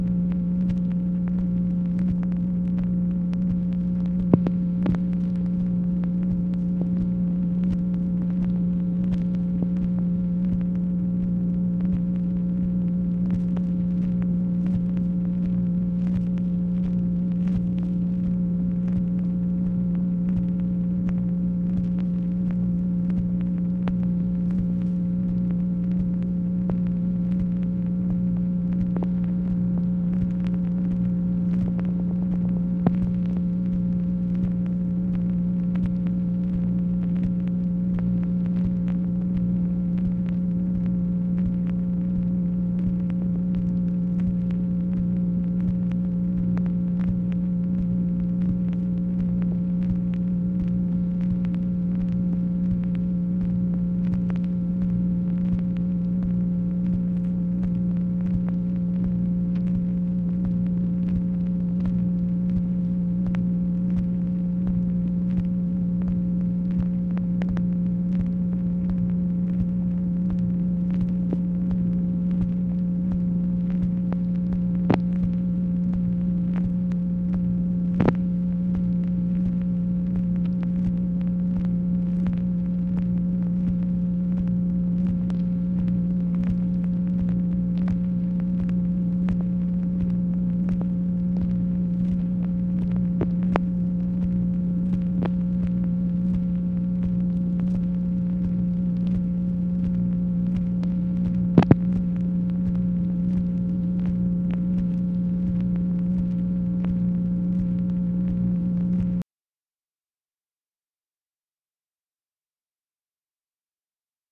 MACHINE NOISE, March 2, 1966
Secret White House Tapes | Lyndon B. Johnson Presidency